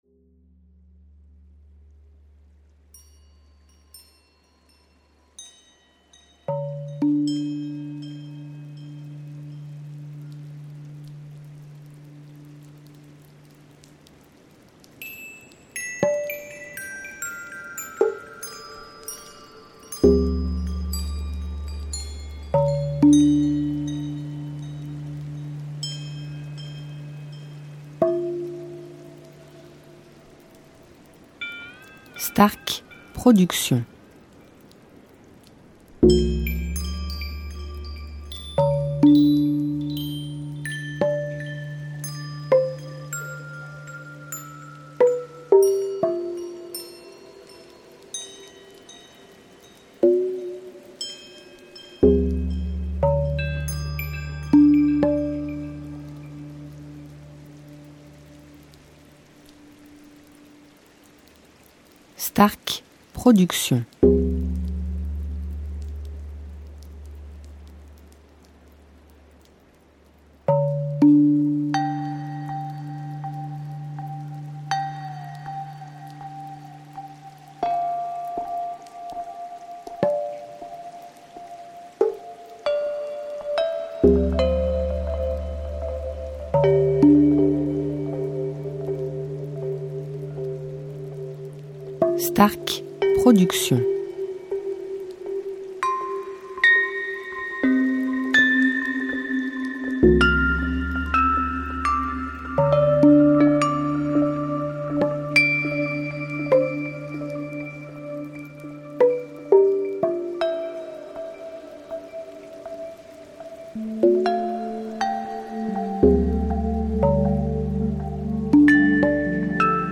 style Sophrologie Méditation durée 1 heure